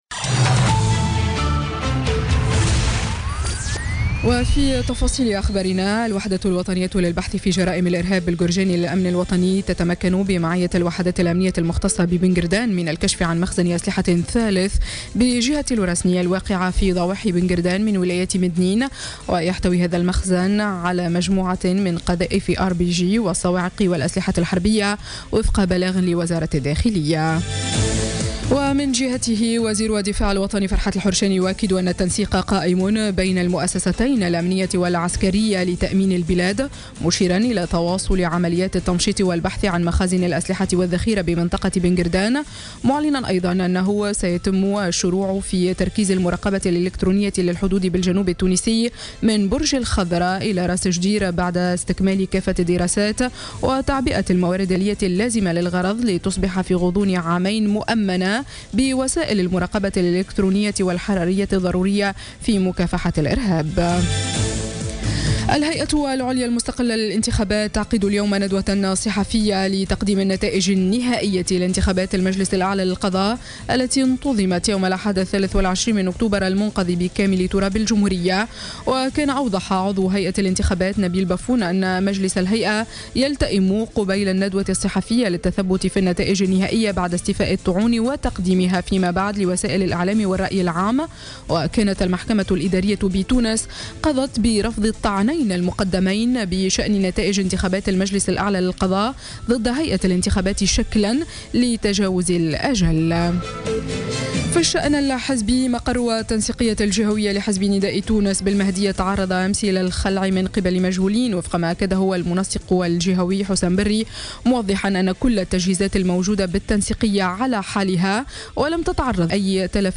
نشرة أخبار السابعة صباحا ليوم الاثنين 14 نوفمبر 2016